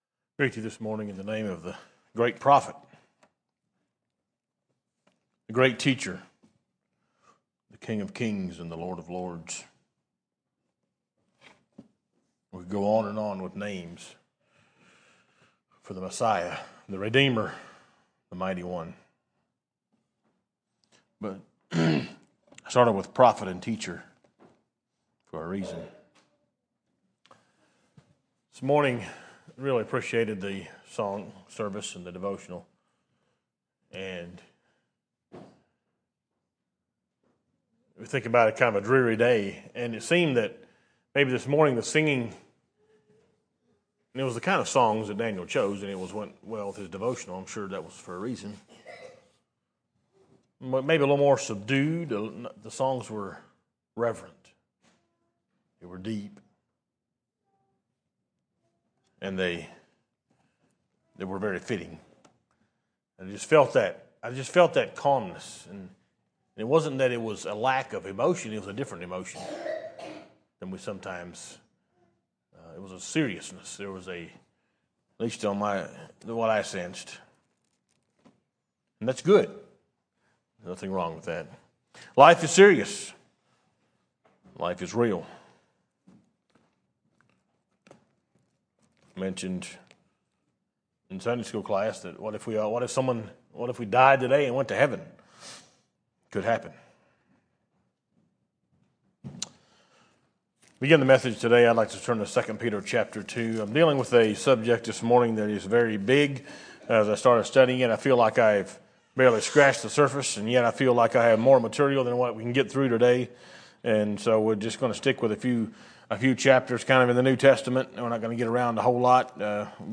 A Sermon By